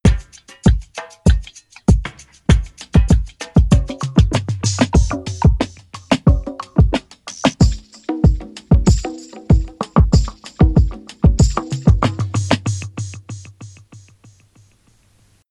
پرکاشن - استودیو موزیک